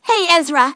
synthetic-wakewords
ovos-tts-plugin-deepponies_Trixie_en.wav